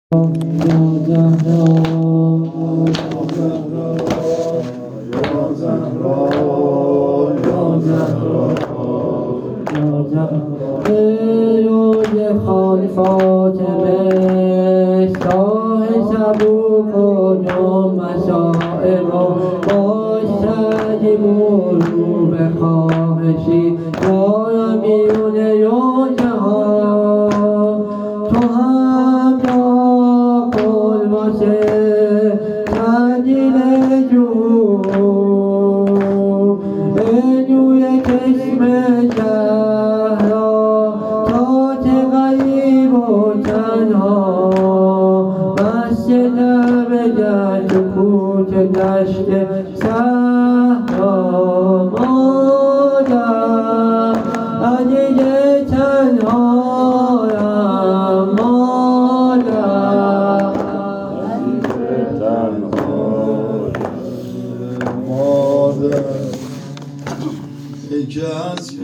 زمینه شهادت فاطمه زهرا
هیئت عشاق العباس تهران